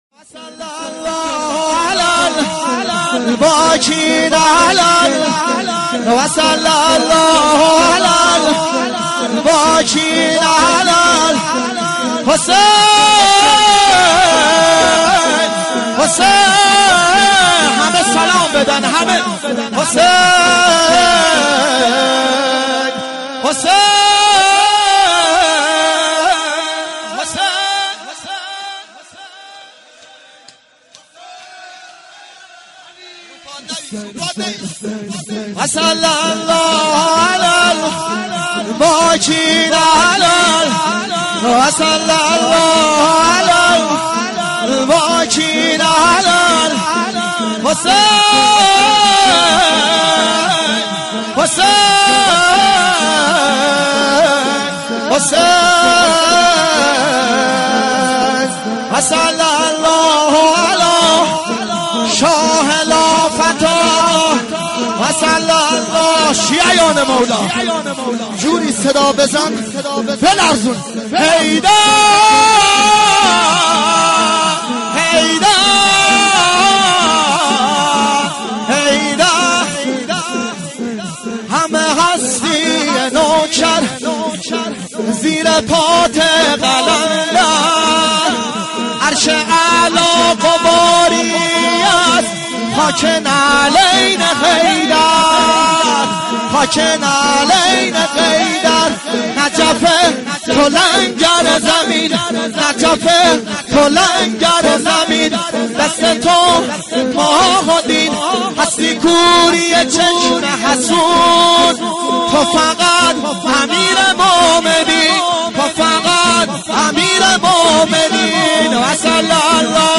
مراسم هفتگی مکتب الزهرا(سلام الله علیها):
شور